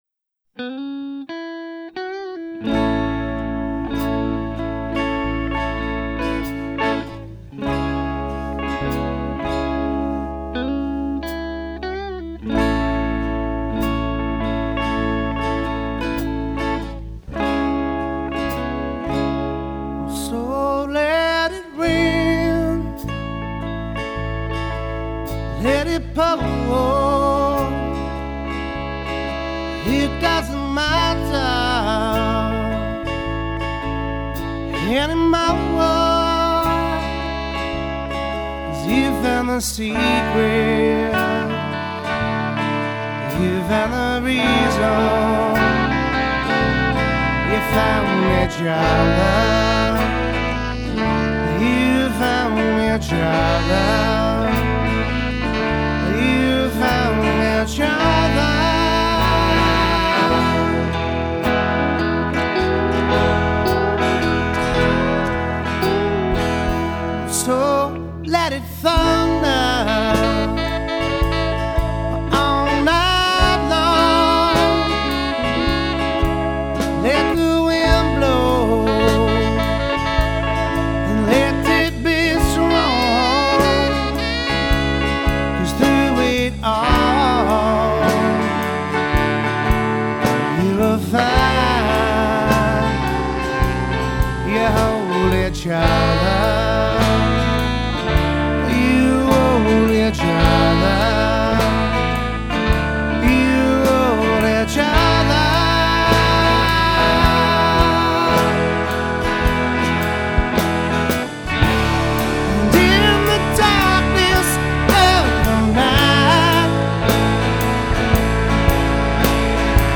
composer & vocals